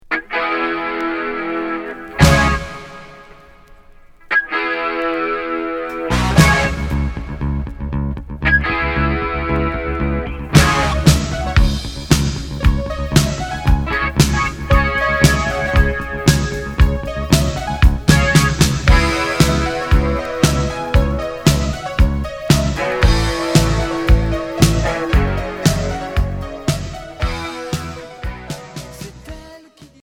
Rock FM